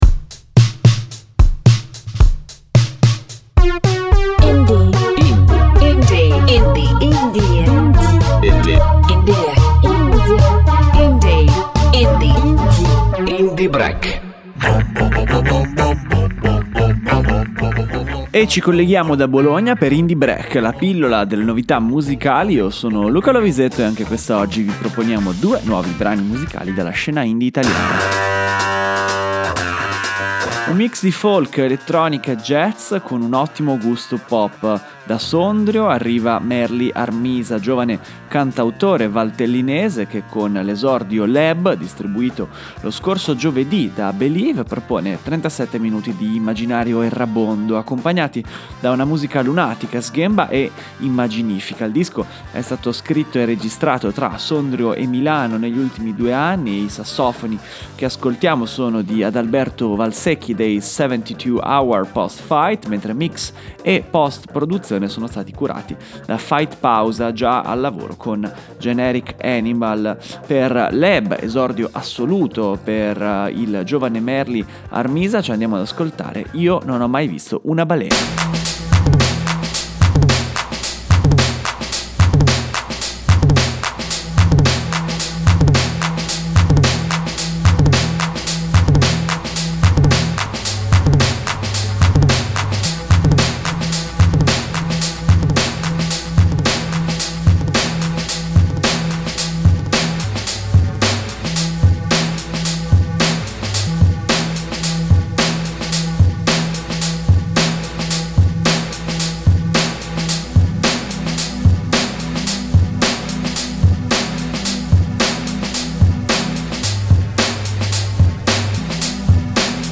Nel brano il cantautore canta la nostalgia per l’estate che volge al termine e la fantasia di figure riflesse sui marciapiedi nonché la vicinanza al freddo.